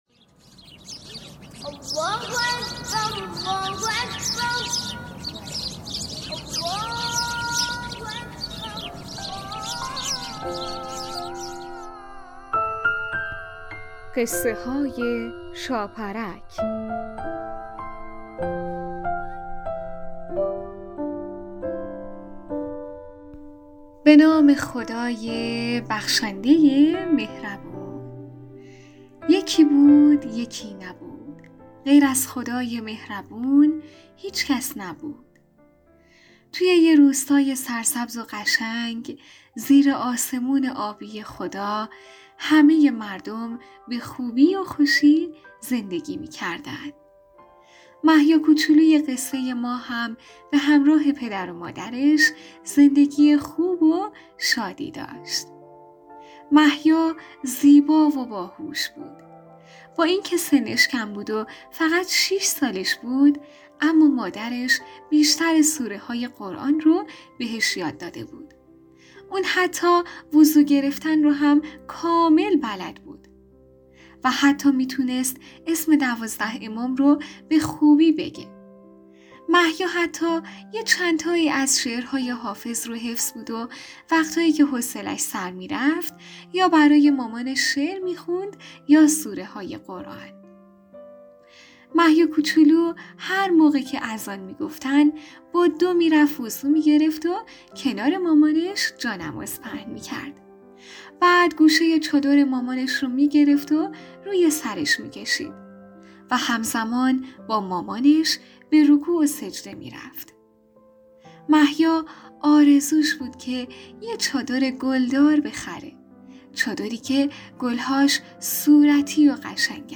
قسمت صد و بیست و ششم برنامه رادیویی قصه های شاپرک با نام چادر محیا یک داستان کودکانه مذهبی با موضوعیت نماز